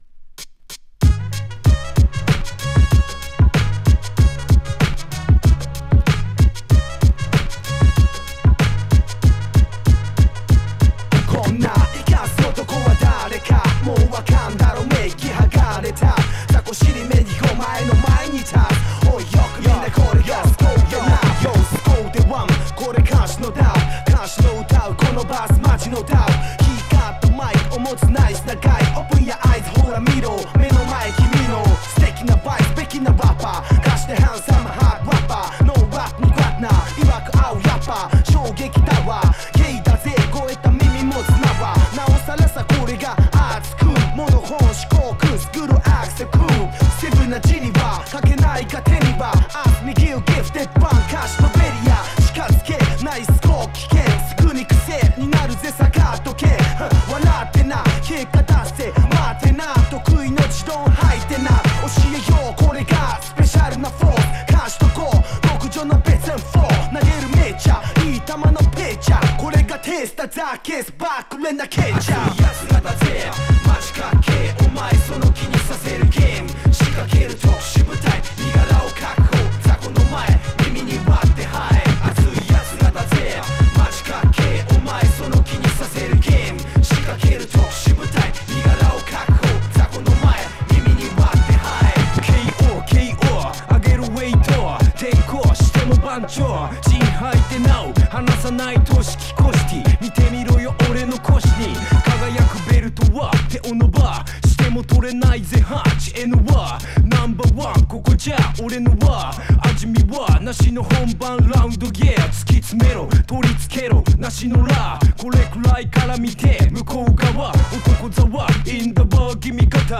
ピアノと ホーンメロディーが激渋の